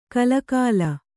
♪ kalakāla